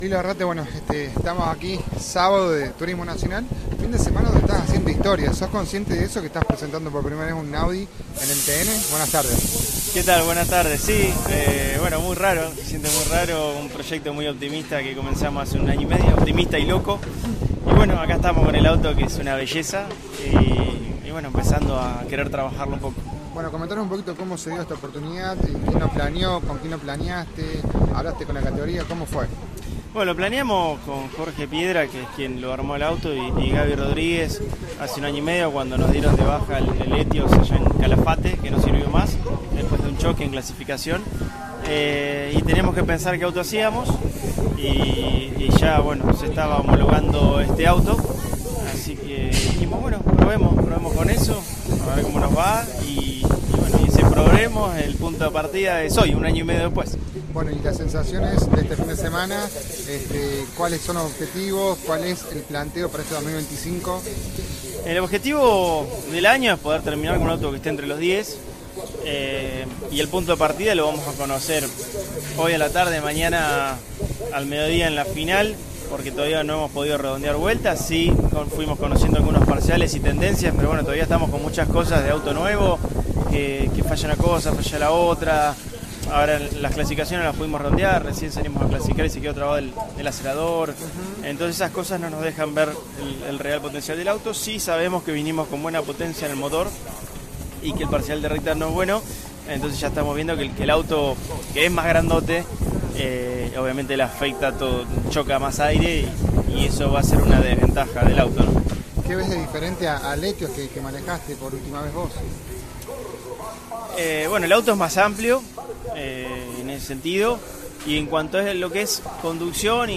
Por tal motivo, dialogamos con el piloto necochense y esa entrevista la podrás escuchar a continuación: